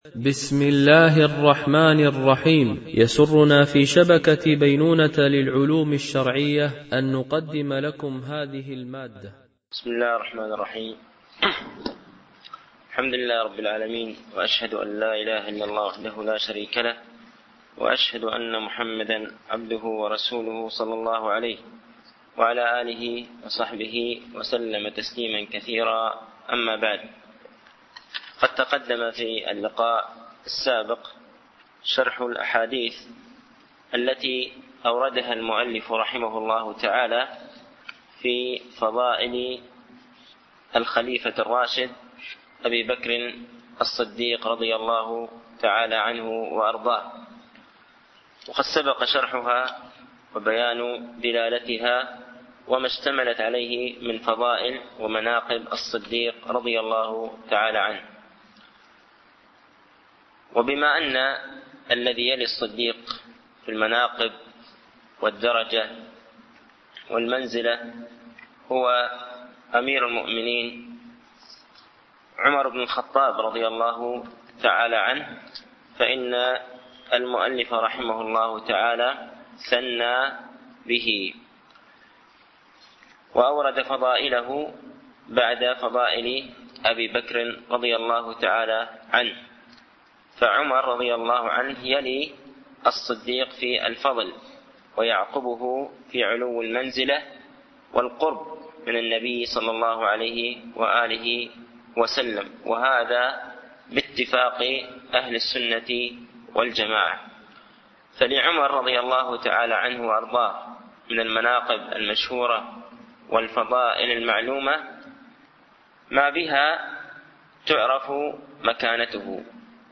) الألبوم: شبكة بينونة للعلوم الشرعية التتبع: 144 المدة: 58:33 دقائق (13.44 م.بايت) التنسيق: MP3 Mono 22kHz 32Kbps (CBR)